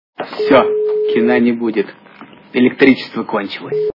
» Звуки » Из фильмов и телепередач » Джентельмен удачи - Всё Кина не будет... Электричество кончилось
При прослушивании Джентельмен удачи - Всё Кина не будет... Электричество кончилось качество понижено и присутствуют гудки.